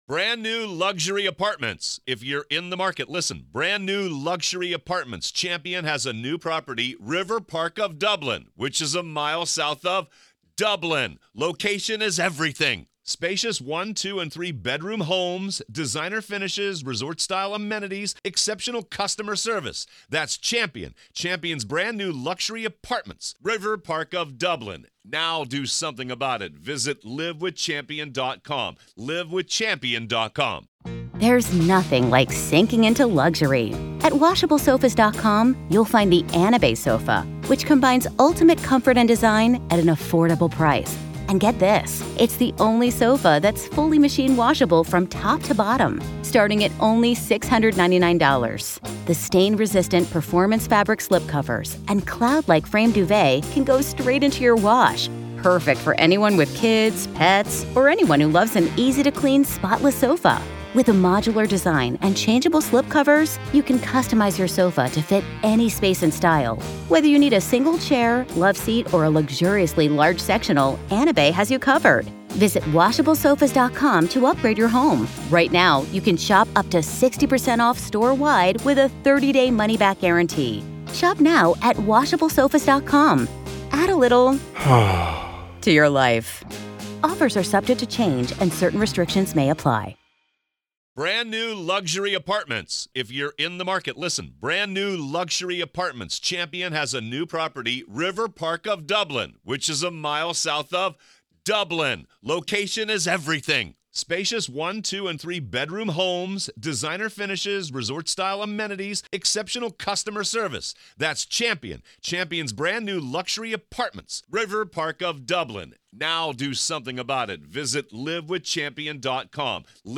Alex Murdaugh Trial: Courtroom Coverage | Day 6, Part 1